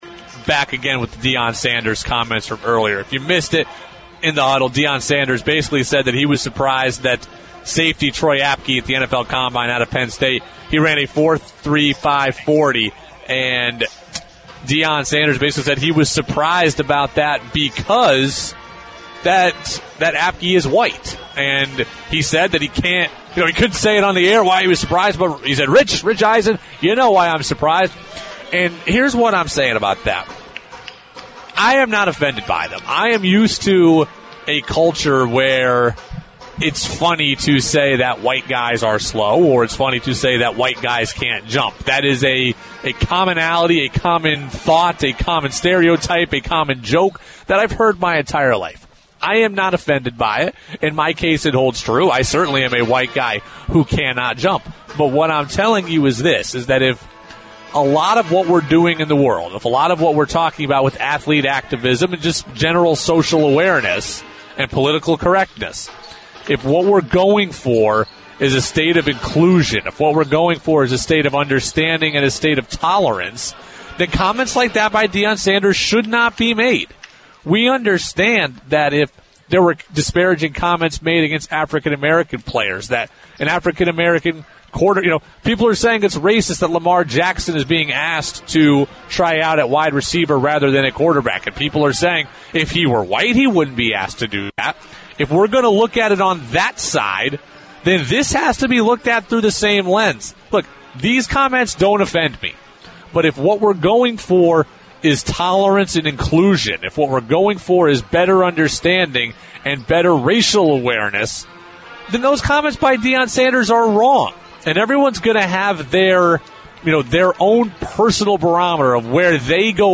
Do we have a problem with those comments? We debated on ‘Champlain Valley Game Night.’